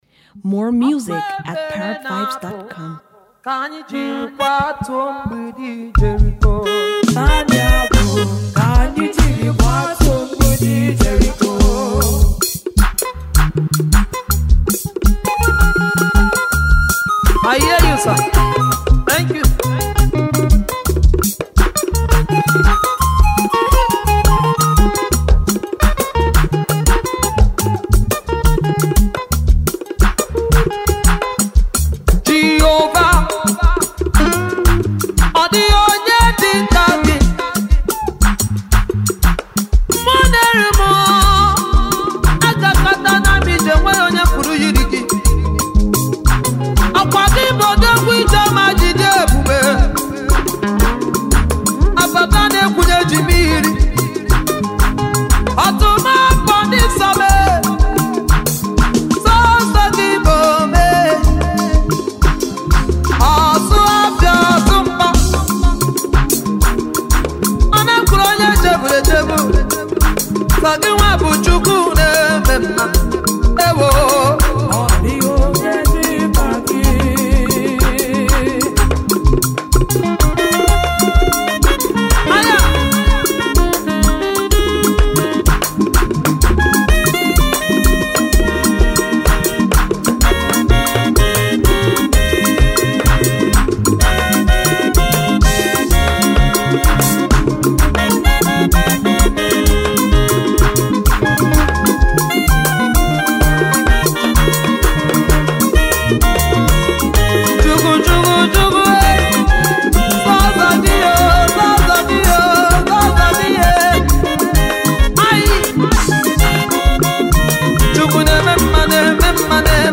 it’s a new sound by Nigerian Gospel singer
recorded live